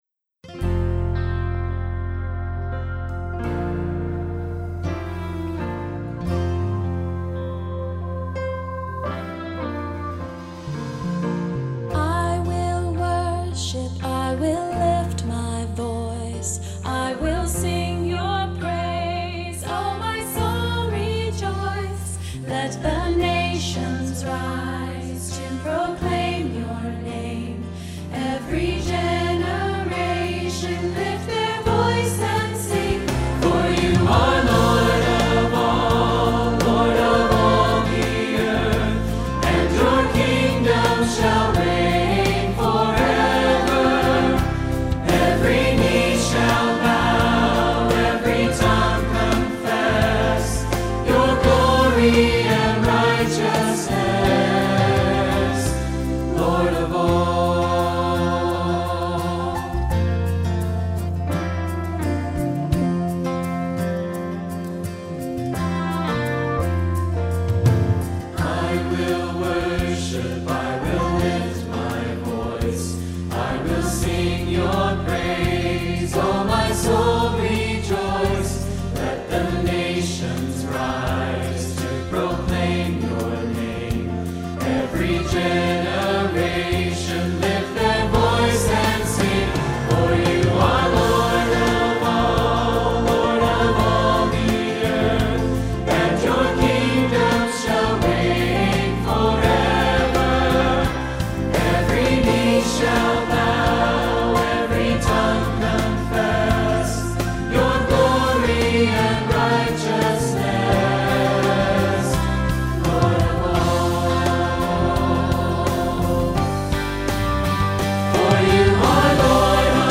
Virtual Choir: I Will Worship
United Church of God 2020 Virtual Festival Choir: This video, featuring vocalists from all over the United States, was played as a special music piece on Day 2 of the 2020 Feast of Tabernacles in Panama City Beach, Florida.